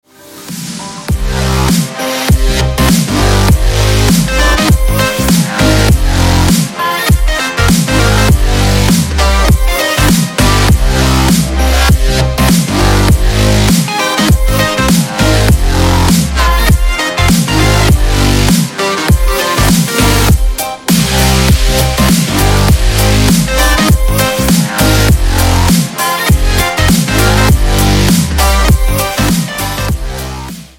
• Качество: 320, Stereo
громкие
мощные
электронная музыка
без слов
Electronica
Downtempo
glitch hop
Бодрая электроника!